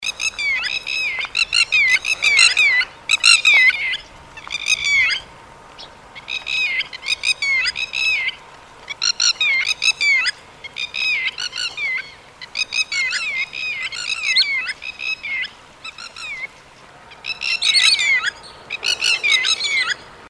На этой странице собраны звуки чибиса — звонкие и мелодичные крики этой птицы.
Звуки чибиса послушать